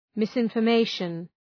Shkrimi fonetik{,mısınfər’meıʃən}